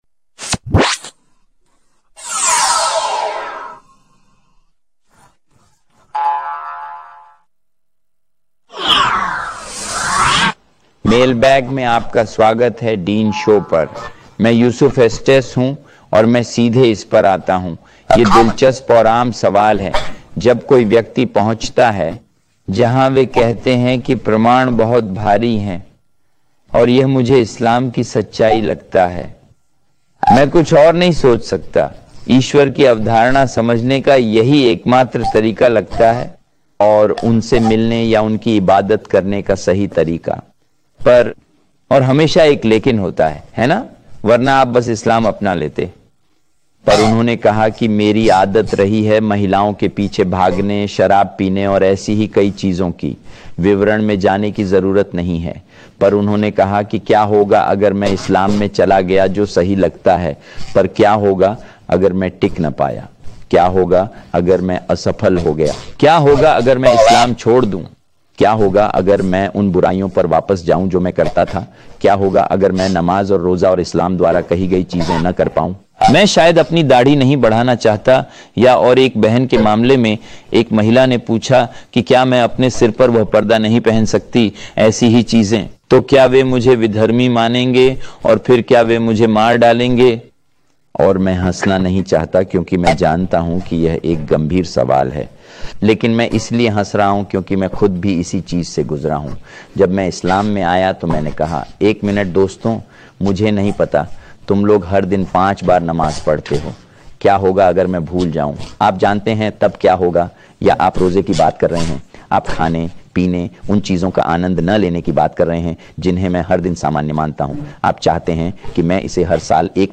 विवरण: अगर कोई व्यक्ति इस्लाम को 100% न निभा सके तो क्या होगा? अगर वह इस्लाम स्वीकार करे और उसे डर हो कि वह अपनी पुरानी आदतों में लौट जाएगा तो? ऐसे अनेक सवालों पर इस व्याख्यान